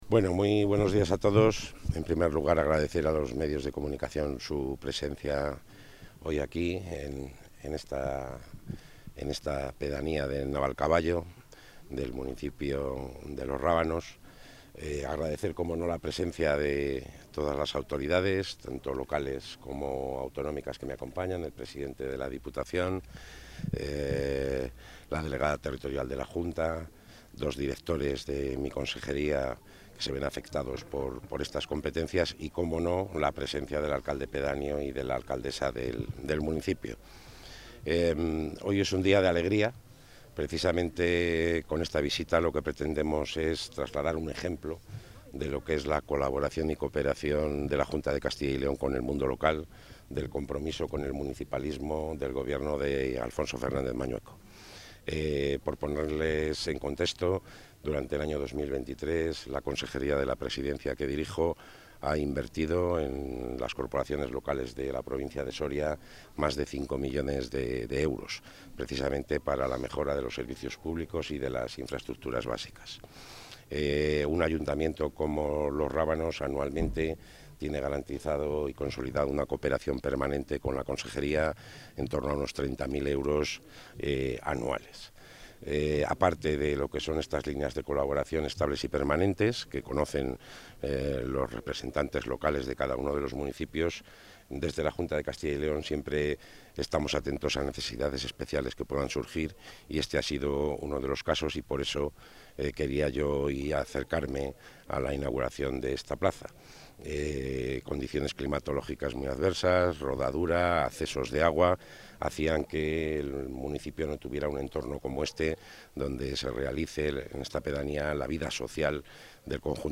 Intervención del consejero.
El consejero de la Presidencia, Luis Miguel González Gago, ha visitado la localidad soriana de Navalcaballo, perteneciente al municipio de Los Rábanos, cuya Plaza Mayor ha sido objeto de una obra de pavimentación y mejora de accesibilidad gracias a los fondos de la Cooperación Local General de dicha Consejería.